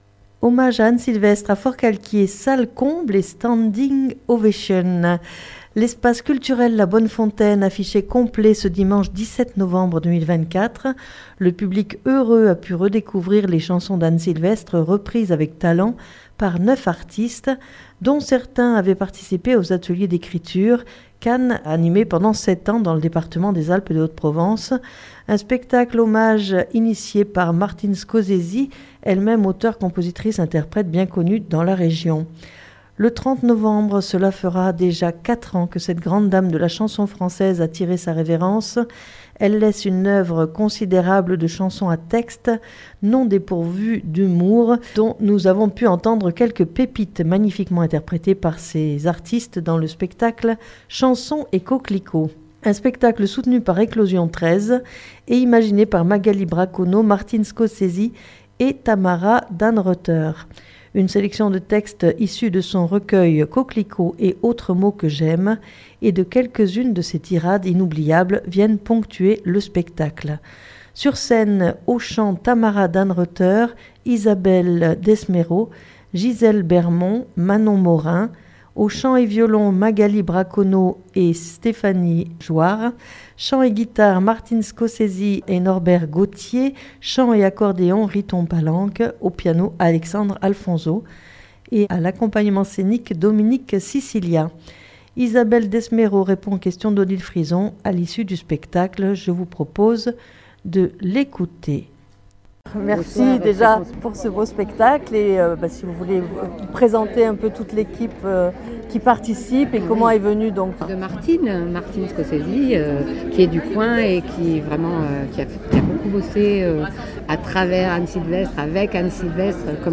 Le 30 novembre, cela fera déjà 4 ans que cette grande Dame de la chanson française a tiré sa révérence. Elle laisse une oeuvre considérable de chansons à textes, dont nous avons pu entendre quelques pépites magnifiquement interprétées par les 9 artistes dans ce spectacle hommage "Chansons et Coquelicots".